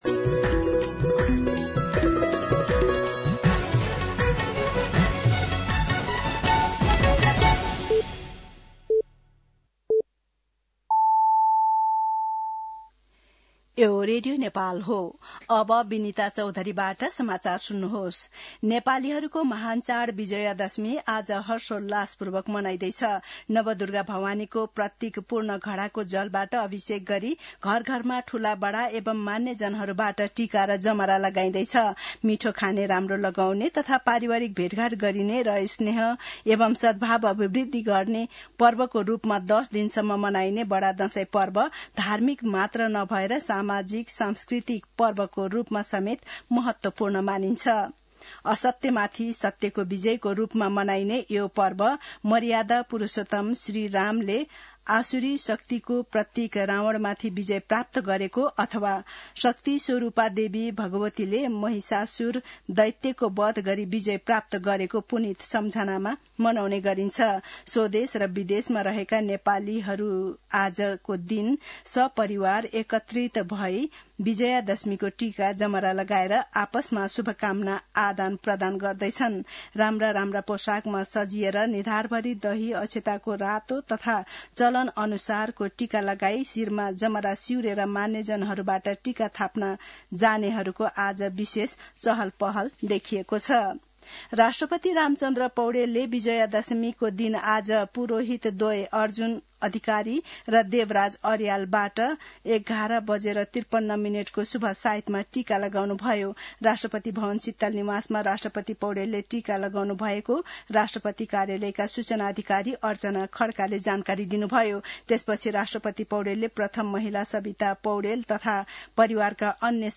साँझ ५ बजेको नेपाली समाचार : १६ असोज , २०८२
5.-pm-nepali-news-.mp3